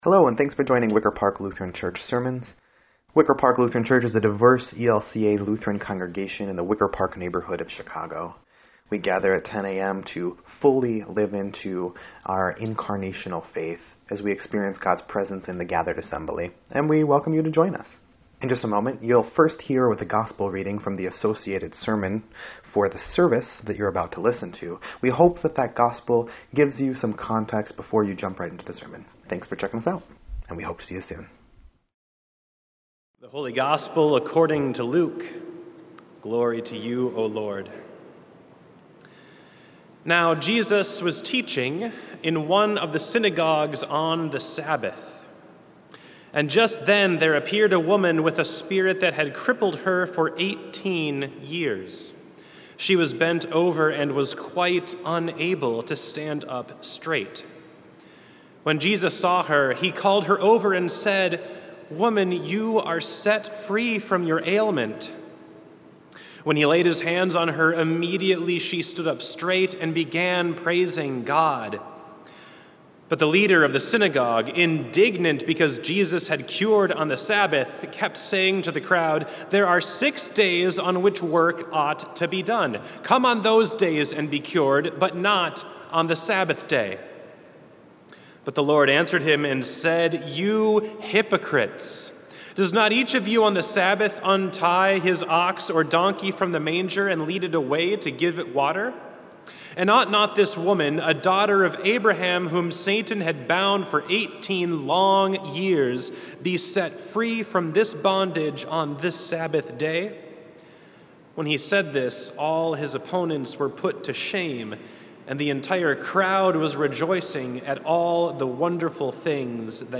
8.21.22-Sermon_EDIT.mp3